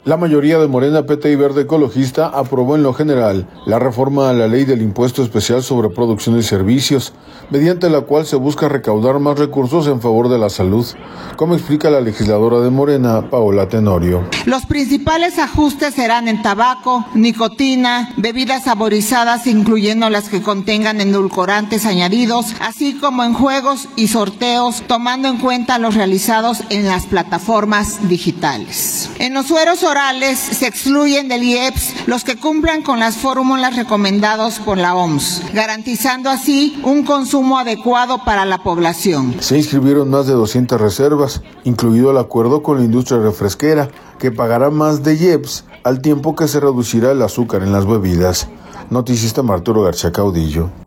La mayoría de Morena, PT y Verde Ecologista aprobó en lo general, la reforma a la Ley del Impuesto Especial sobre Producción y Servicios, mediante la cual se busca recaudar más recursos en favor de la salud, como explica la legisladora de Morena, Paola Tenorio.